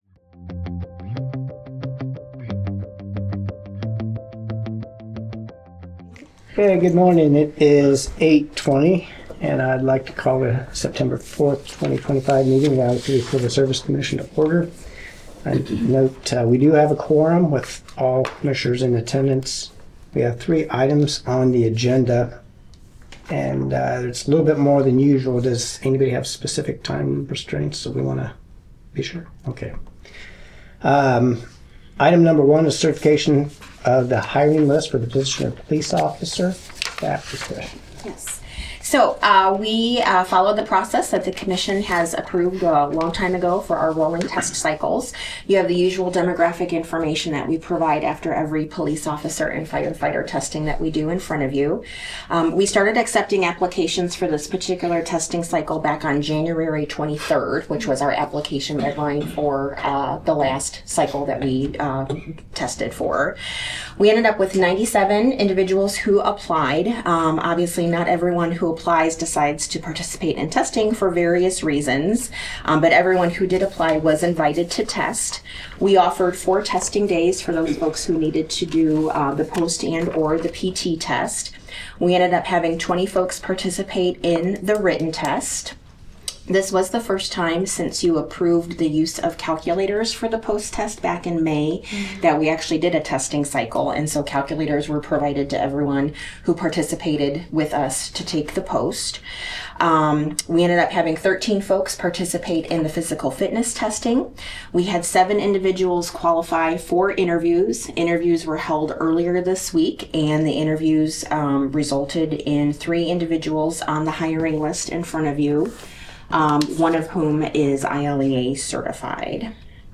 A meeting of the City of Iowa City's Civil Service Commission.